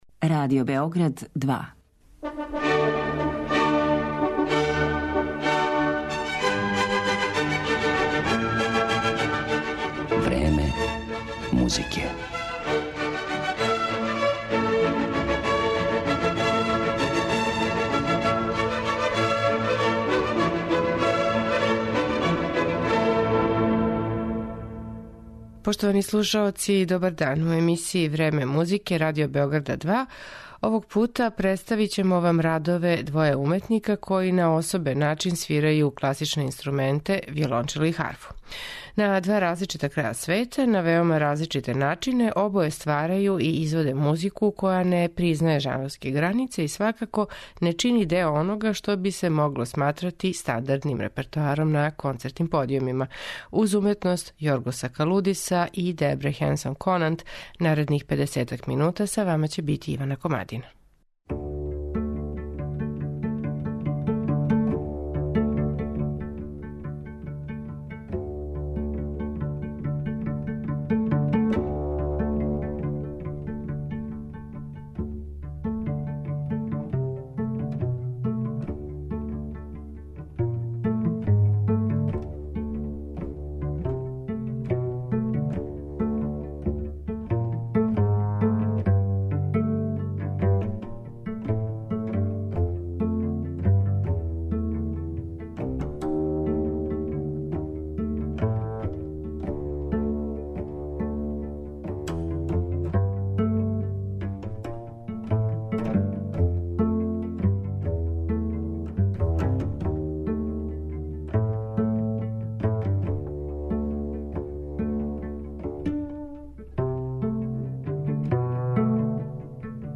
У данашњем 'Времену музике' представићемо радове двоје уметника који на особен начин свирају класичне инструменте, виолончело и харфу.